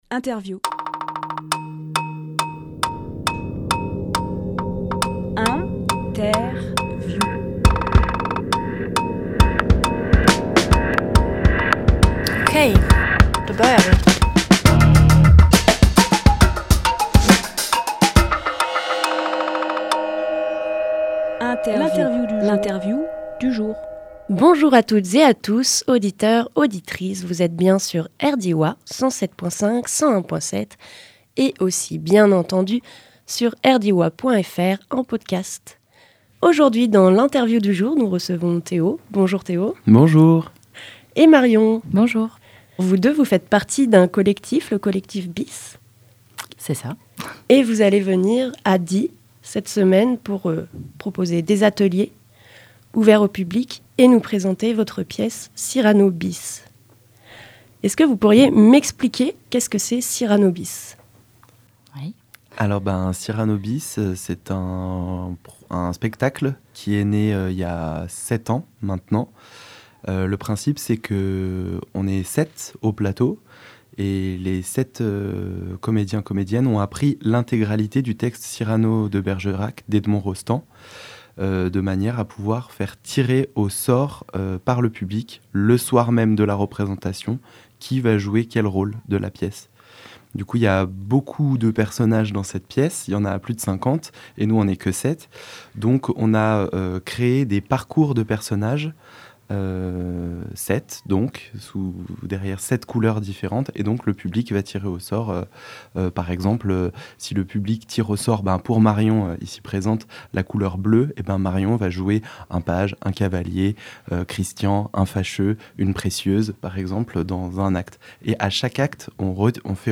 Emission - Interview Le « Collectif Bis » nous présente leur pièce de théâtre « Cyrano Bis » Au Théâtre Les Aires.